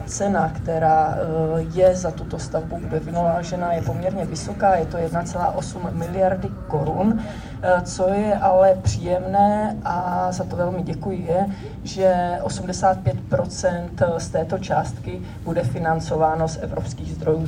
Pokračuje primátorka města Brna Markéta Vaňková.